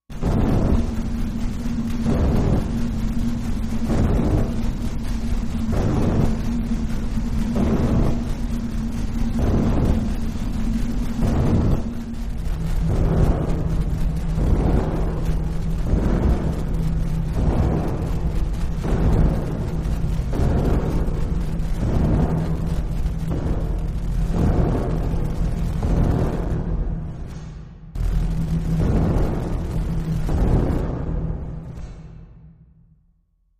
Thunder Maker, Machine, Low Thunder like, Rhythm, Low Air Pulse